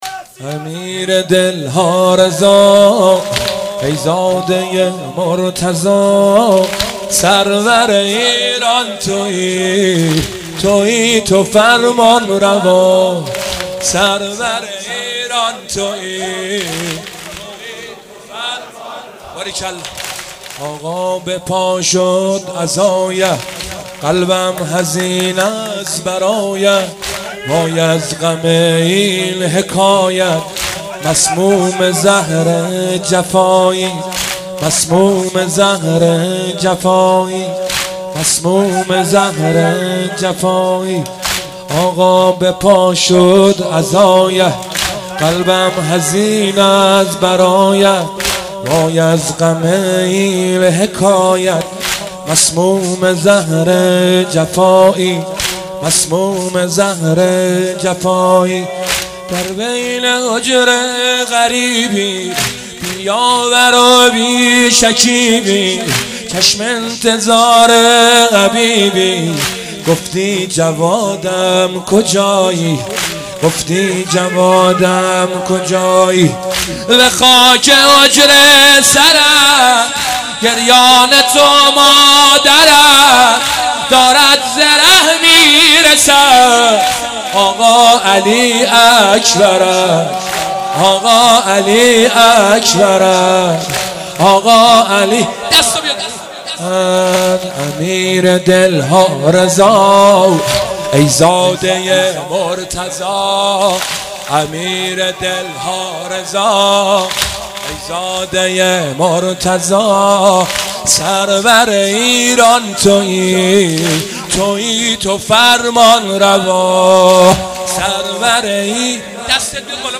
شور و دمام زنی